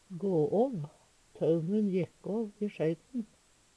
gå åv - Numedalsmål (en-US)